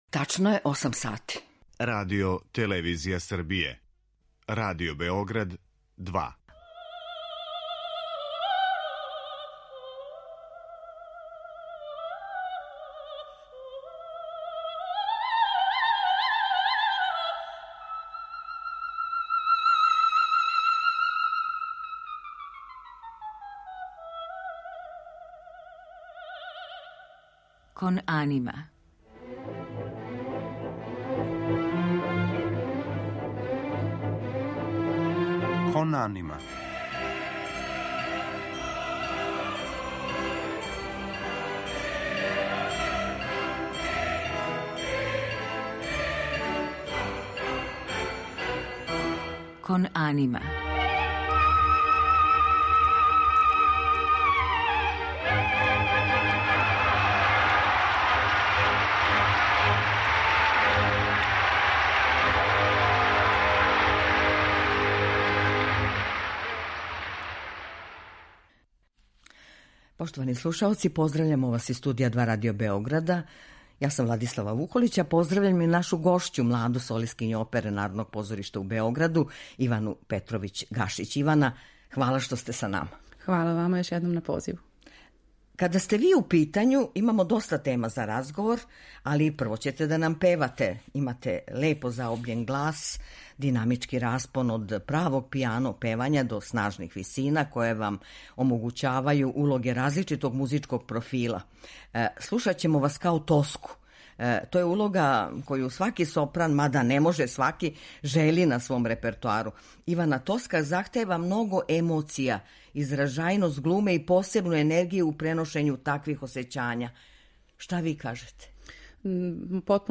сопран
Представиће се као тумач сопранског репертоара, кроз арије из опера Ђакома Пучинија и Ђузепа Вердија.